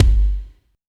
18 KICK.wav